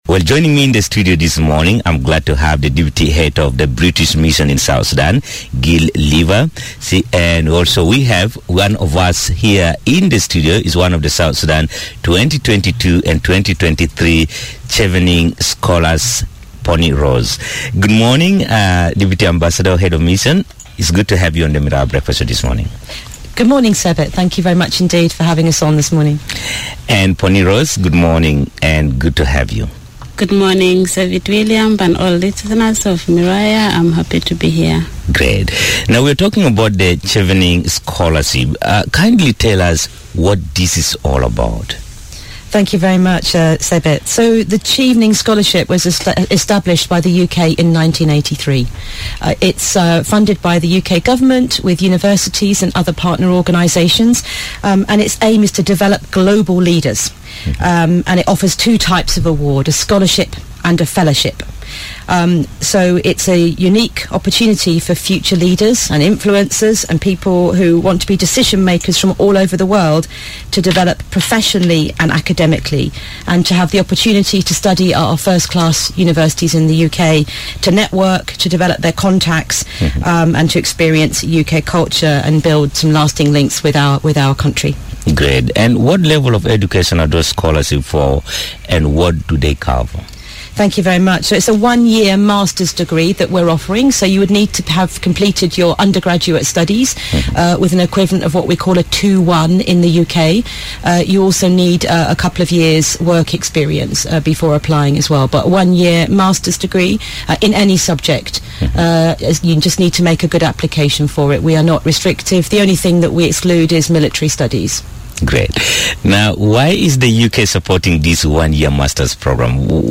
Education is said to be a powerful tool that opens doors to a lot of opportunities in one’s life. If achievement and preparation for global competitiveness is your desire, then please take a listen to this interview which informs you about the available scholarships that could...